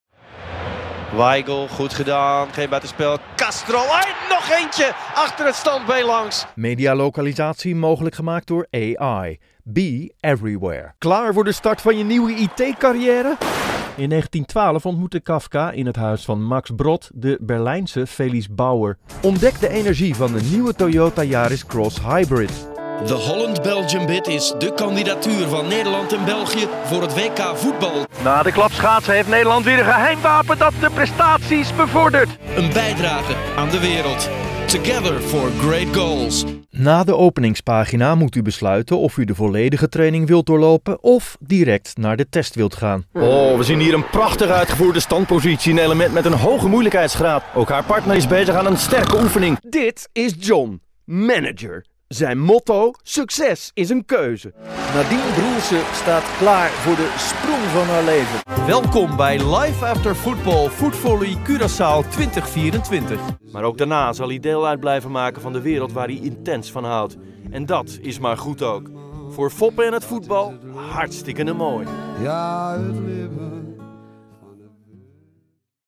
Motivacional
Expresivo
Amistoso